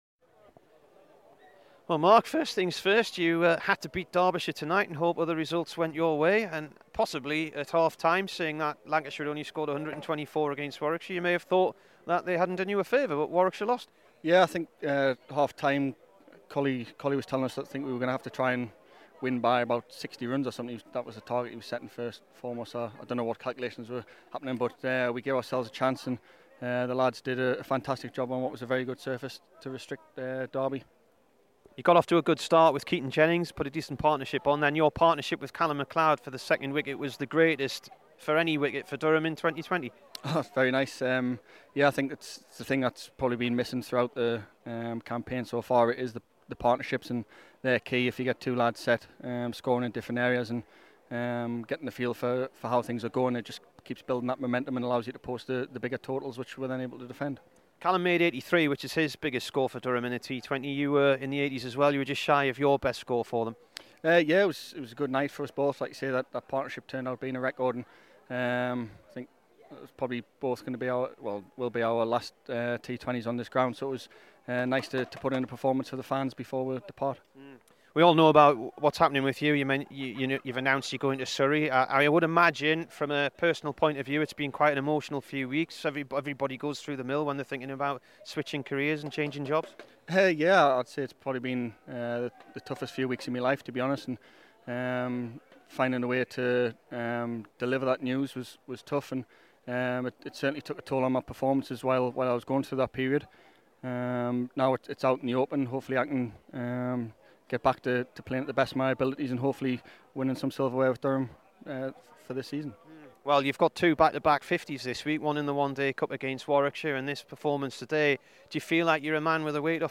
Here is the Durham batsman after his 82* not out in the T20 win over Derbyshire.